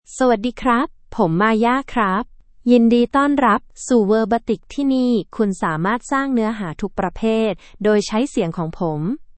Maya — Female Thai AI voice
Maya is a female AI voice for Thai (Thailand).
Voice sample
Listen to Maya's female Thai voice.
Female
Maya delivers clear pronunciation with authentic Thailand Thai intonation, making your content sound professionally produced.